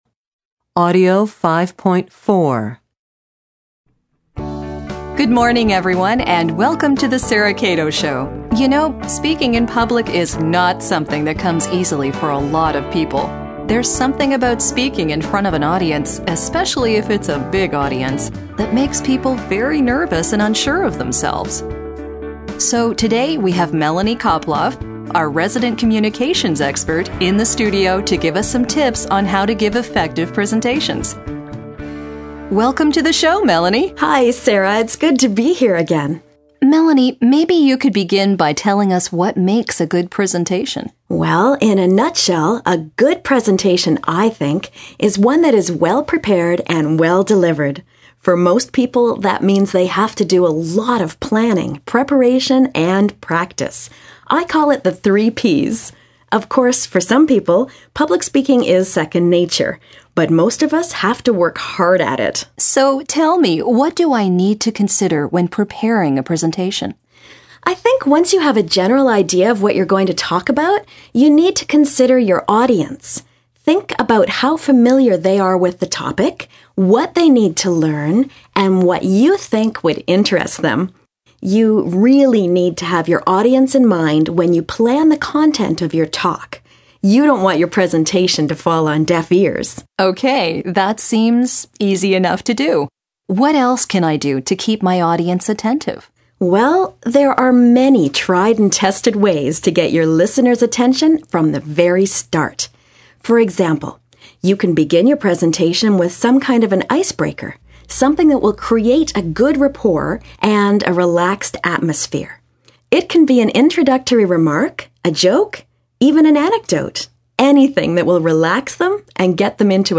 a radio interview with a communications consultant about giving presentations.